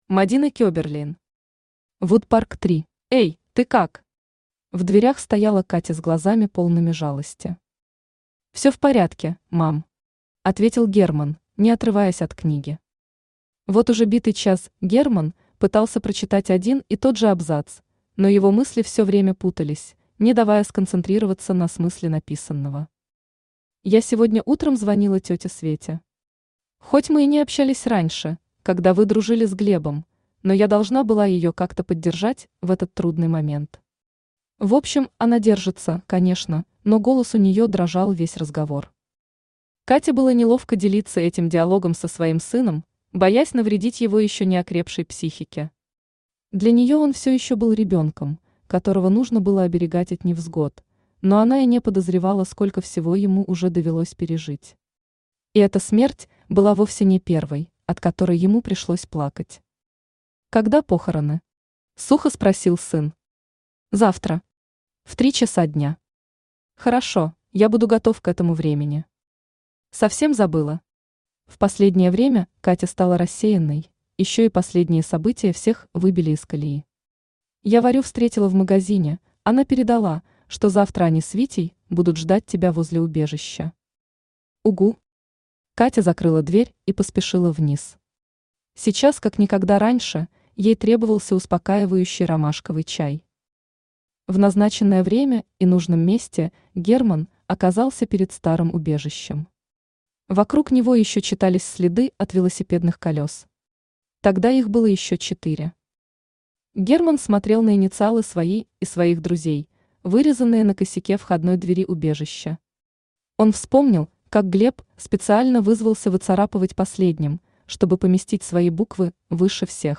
Аудиокнига Woodpark 3 | Библиотека аудиокниг
Aудиокнига Woodpark 3 Автор Мадина Кеберлейн Читает аудиокнигу Авточтец ЛитРес.